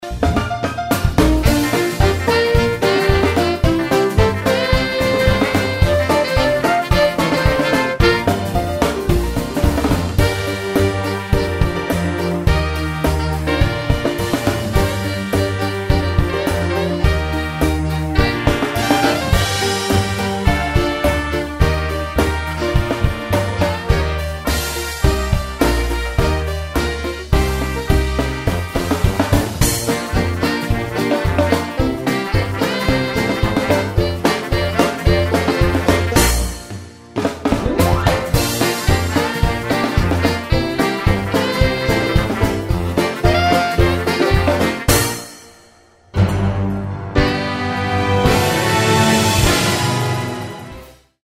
performance track
backing track
Instrumental
orchestral